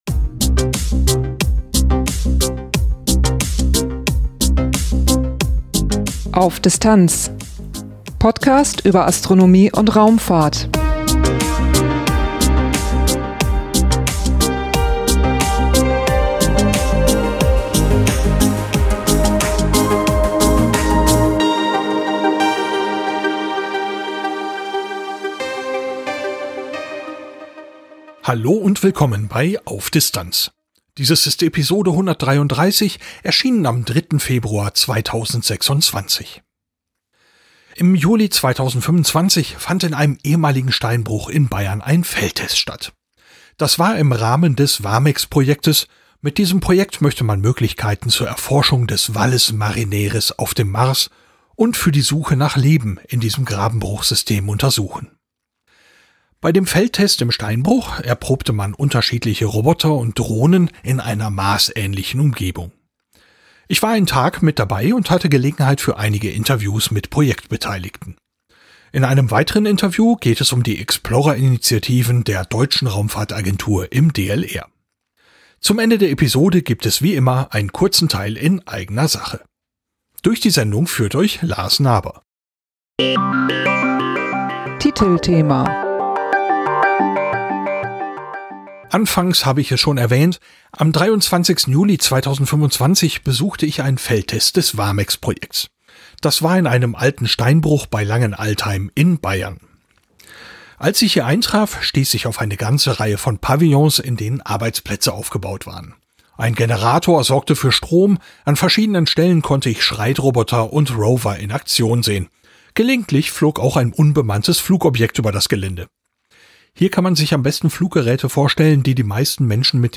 Ich war einen Tag mit dabei und hatte Gelegenheit für einige Interviews mit Projektbeteiligten. In einem weiteren Interview geht es um die Explorer-Initiativen der Deutschen Raumfahrtagentur im DLR.